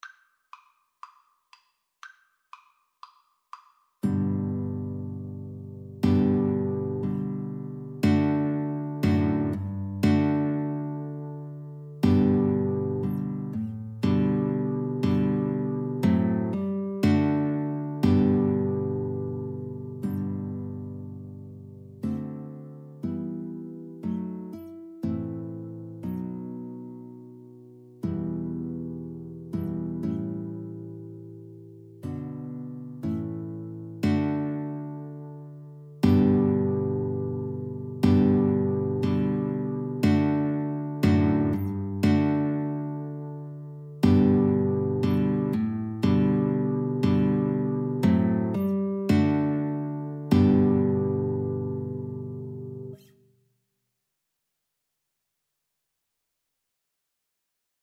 4/4 (View more 4/4 Music)
Allegro = c. 120 (View more music marked Allegro)
Classical (View more Classical Guitar-Flute Duet Music)